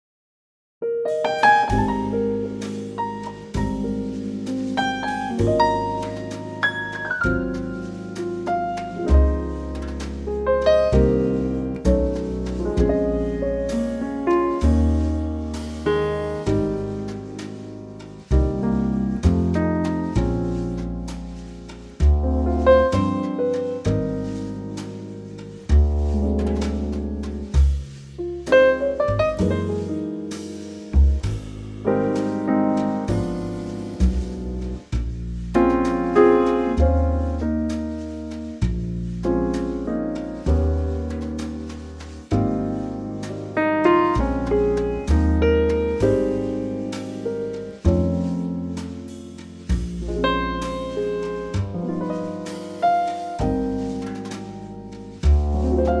key Ab
key - Ab - vocal range - B to Db
Very lovely 40's ballad